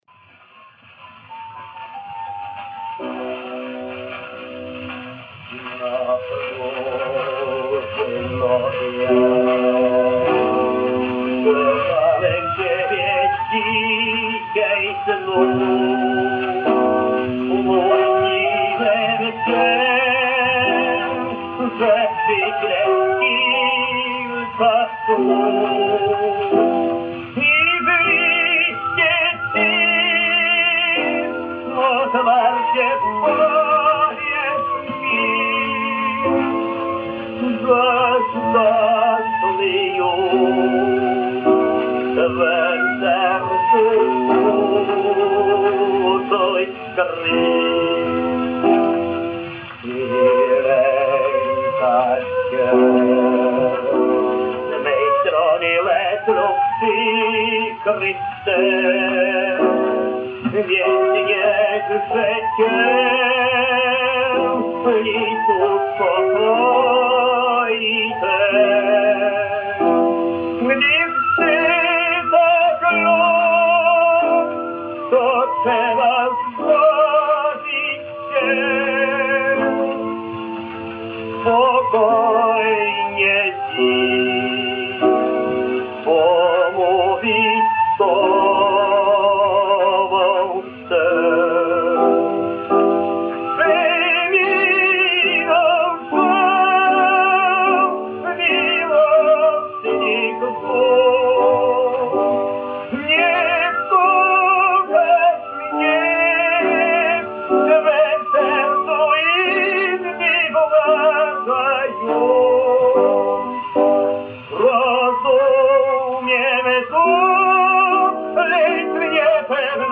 Польская версия австро-венгерского шлягера